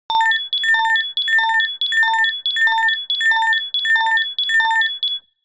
フリー音源効果音「警告音」「緊急音」です。
フリー音源 「警告音」「緊急音」3
緊急音3